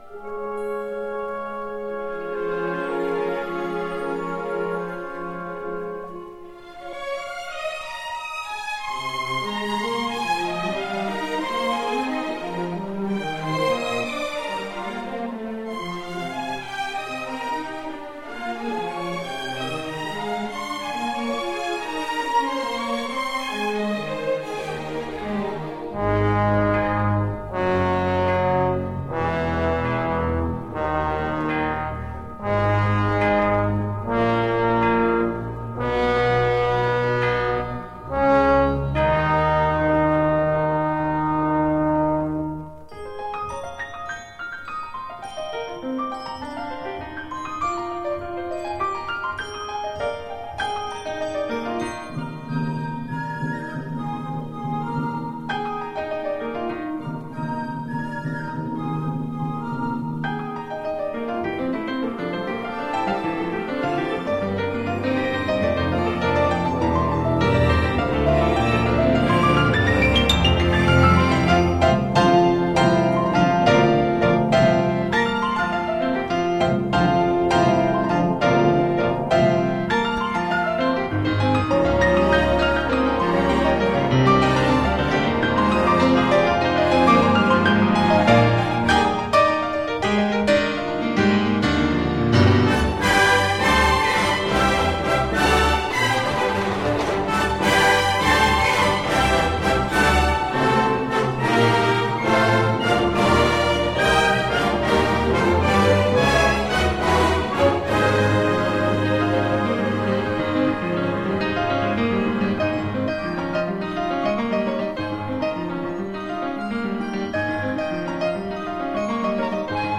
an English keyboardist and composer.
progressive rock band